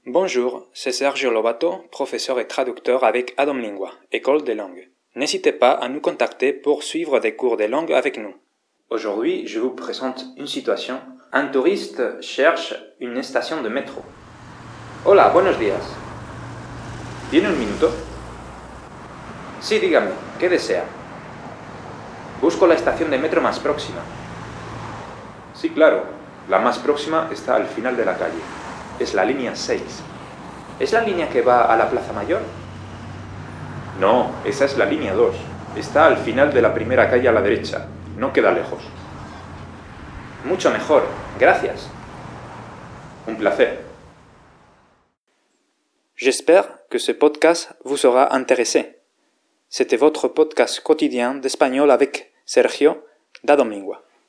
Cours d’espagnol – Chercher une station de métro
présente une scène de la vie courante avec un passant qui cherche une station de métro.